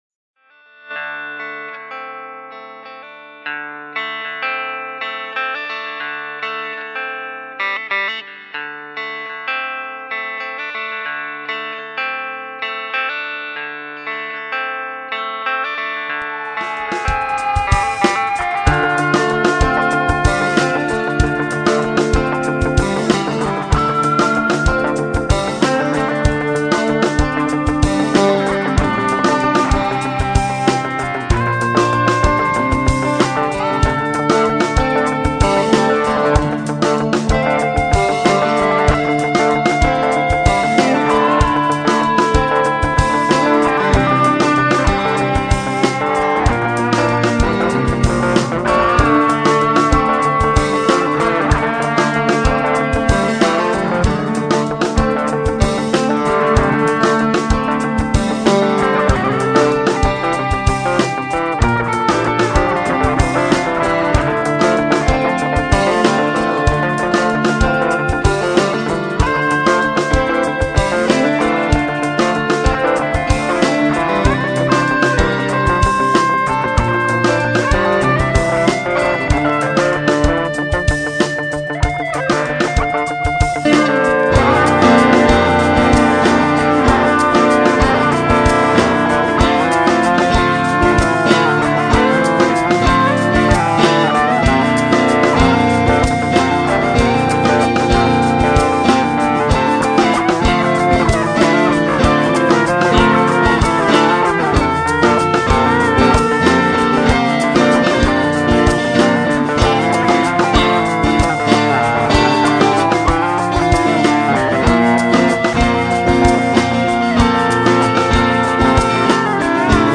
Емоції чужих (гитарная импровизация)